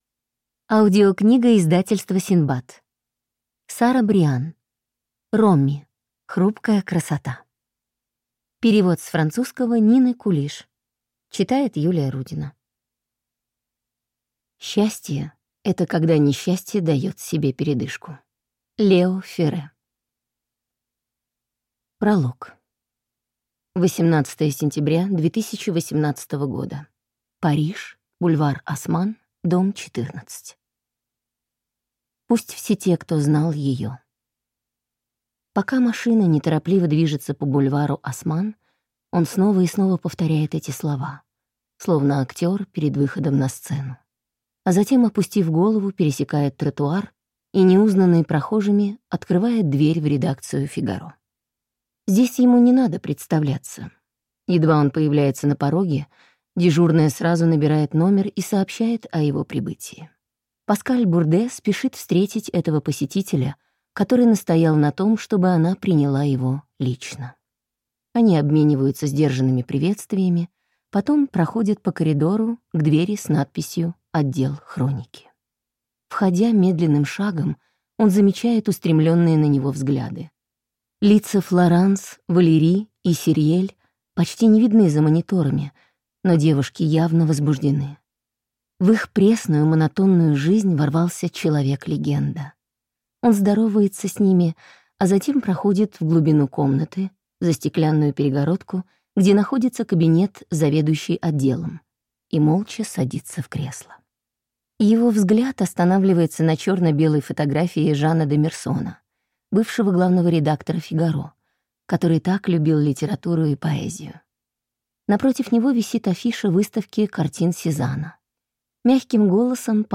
Аудиокнига Роми. Хрупкая красота | Библиотека аудиокниг